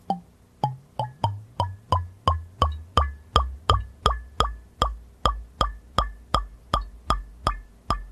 朝から新しい酒(※)を開けたので録音してみました。フリー素材として公開しますのでご自由にお使いください。